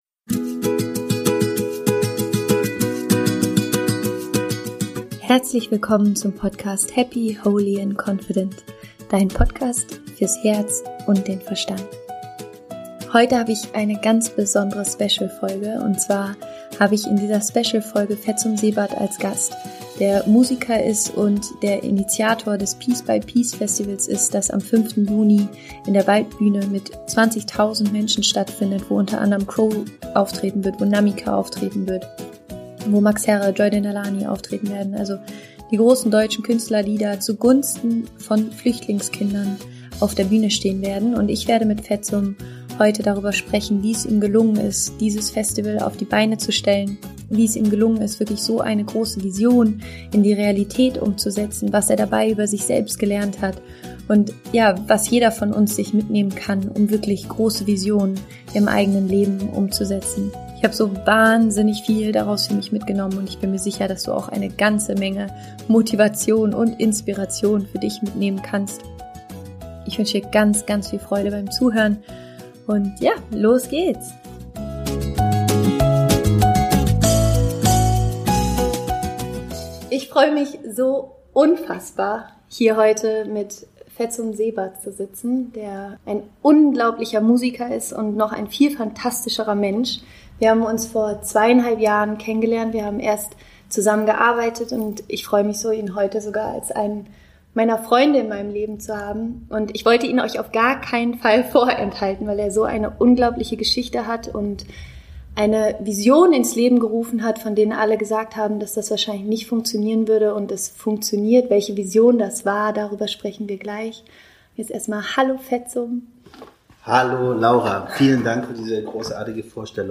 Heute im Interview-Special: